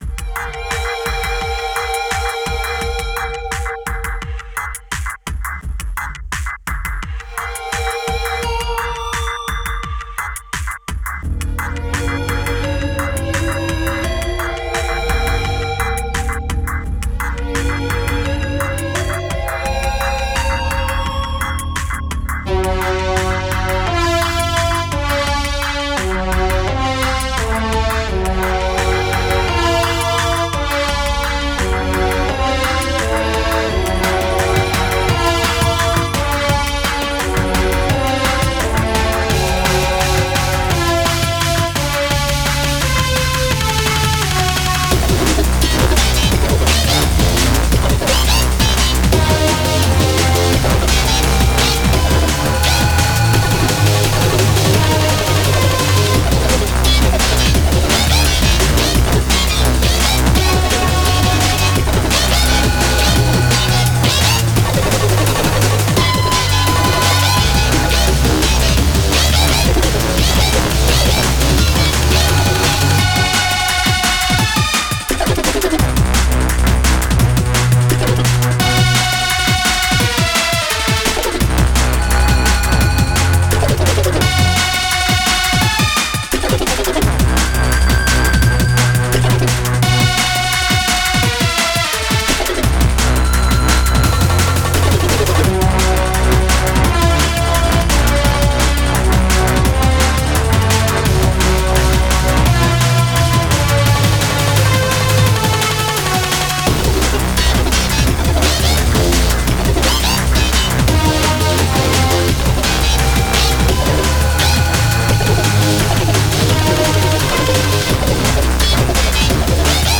microtonal DNB attempt. First time really working with odd scales
microtonal drumbass electronic
LOVED the percussion a lot, and those leads were sharp as heck!!!